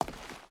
Footsteps / Stone / Stone Walk 4.ogg
Stone Walk 4.ogg